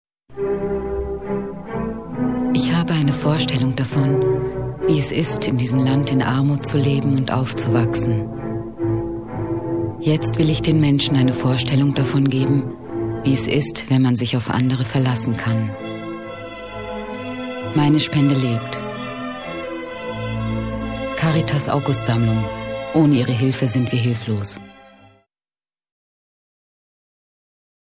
Sprecherin türkisch, Schauspielerin.
Sprechprobe: Sonstiges (Muttersprache):
turkish female voice over artist.